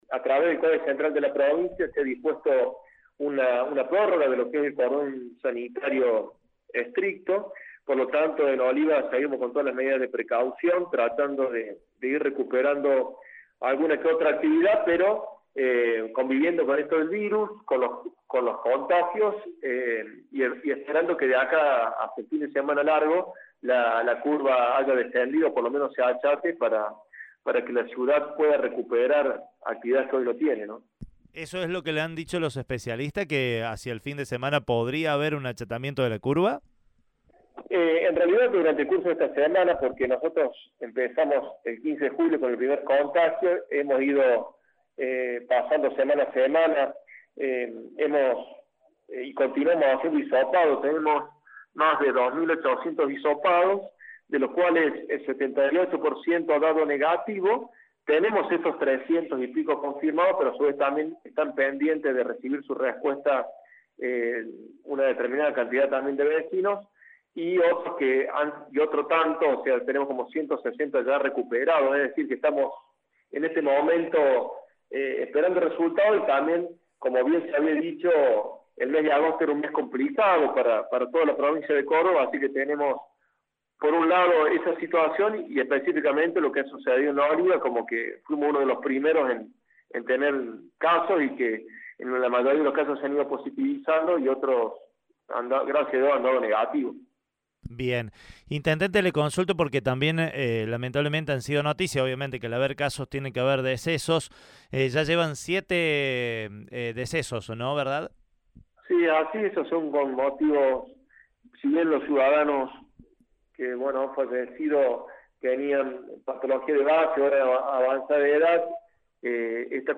El intendente de Olivas, Octavio Ibarra habló con nuestro medio.